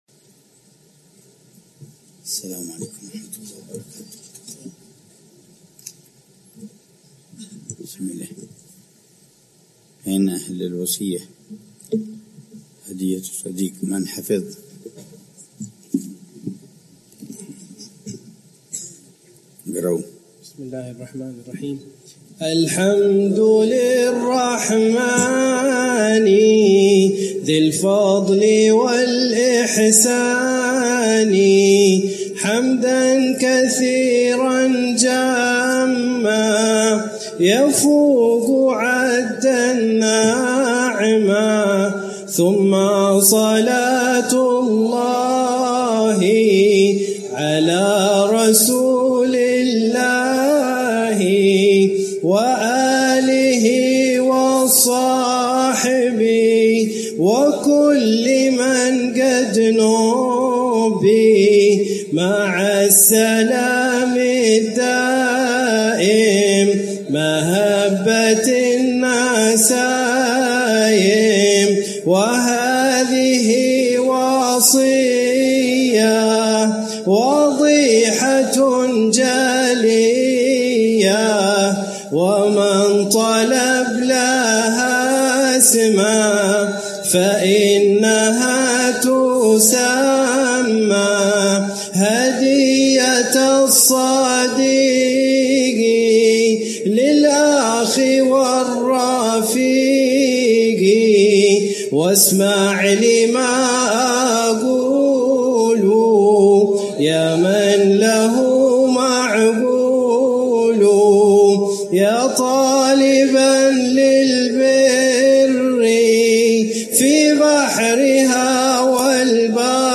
الدرس السادس (16 محرم 1447هـ)